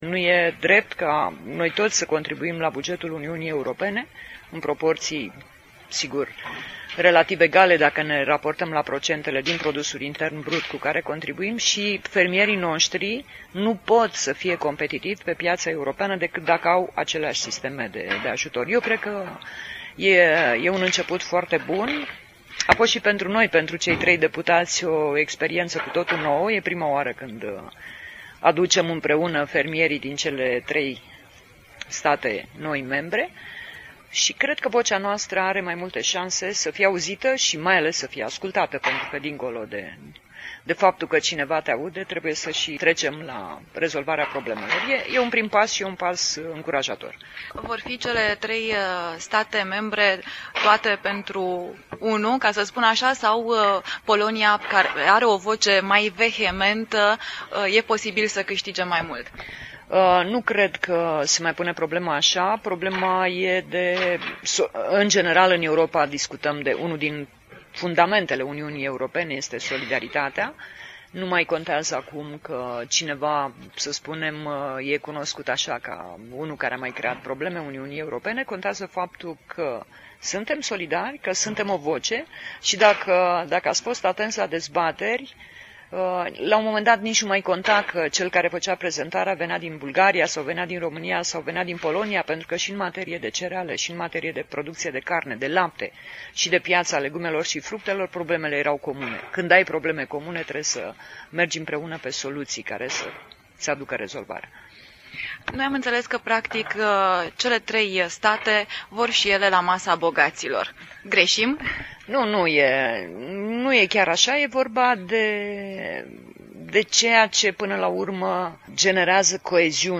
La finalul dezbaterilor, eurodeputatul Maria Petre a acordat un interviu pentru Radio CAMPUS in care explica demersul parlamentarilor europeni din Romania, Bulgaria si Polonia pentru a-i ajuta pe fermierii din tarile lor.
Europarlamentarul Maria Petre, intr-un interviu